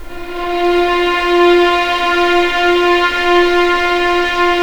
F4LEGPVLN  R.wav